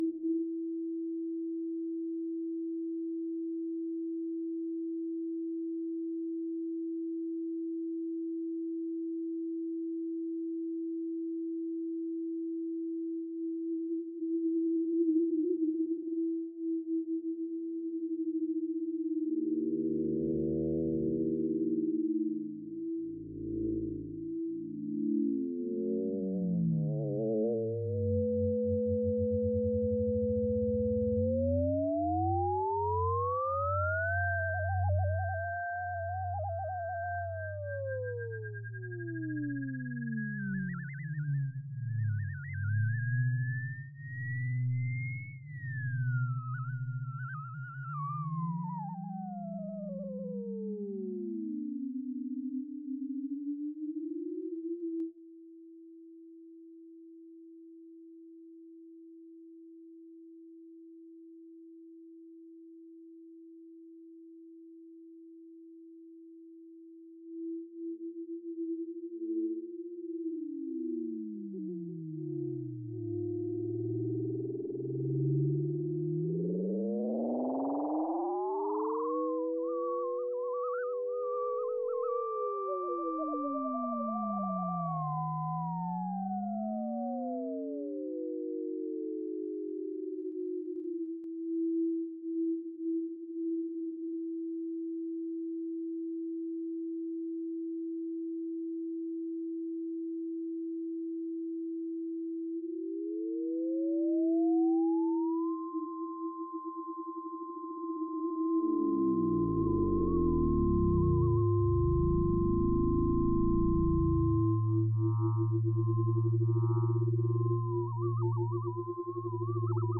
Schermaglie tra UFO nani - [ mp3 ] Electronics.